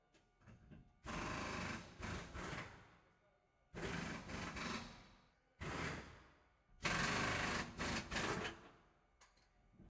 Pole Position - BMW Z4 GT3 racecar
BMW_Z4_GT3_t6_wild_foley_wheel_machine_interior.ogg